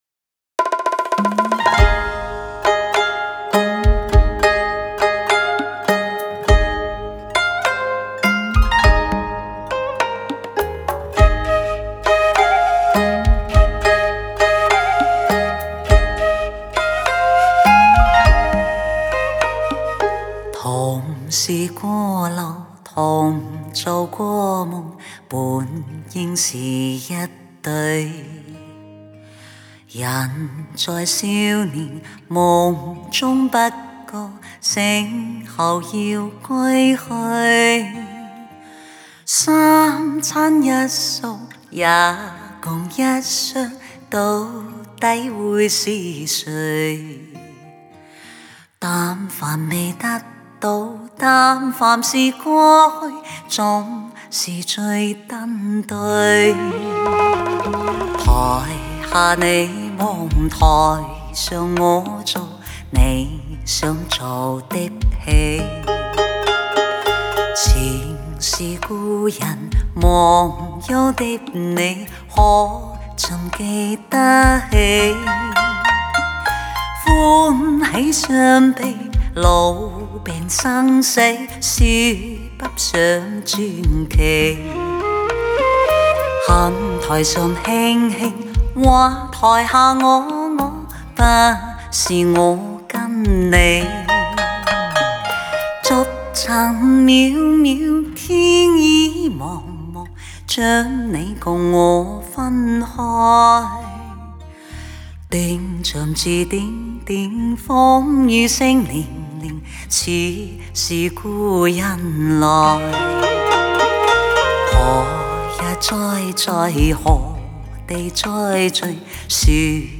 国语流行